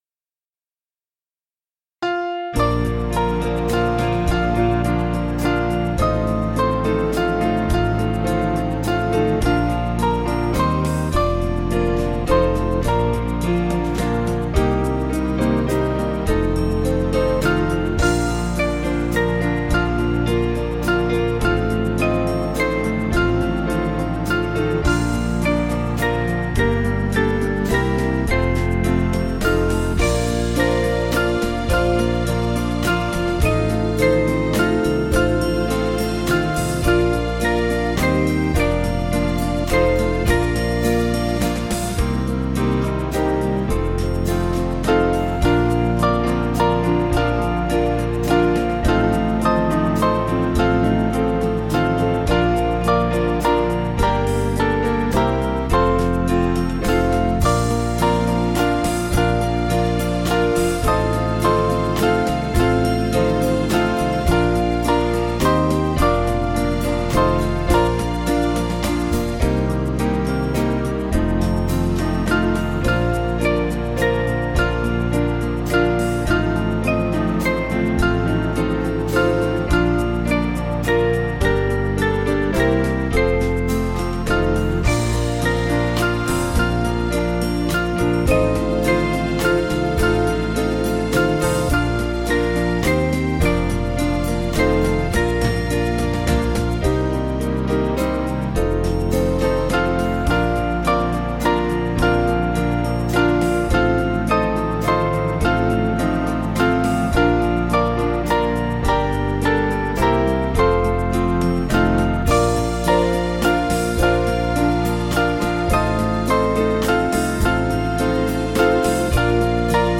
Small Band
(CM)   4/Bb 486.8kb